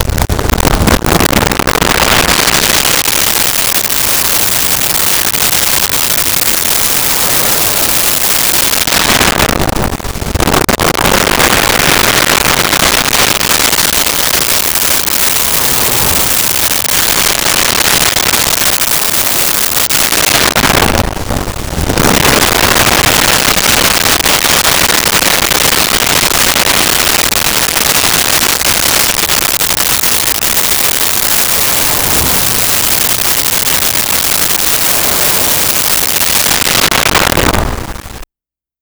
City Traffic Car Bys
City Traffic Car Bys.wav